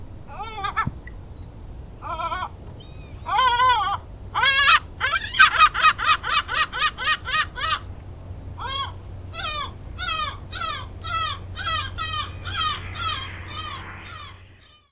kleine mantelmeeuw
🔭 Wetenschappelijk: Larus fuscus
♪ contactroep
kleine_mantelmeeuw_roep.mp3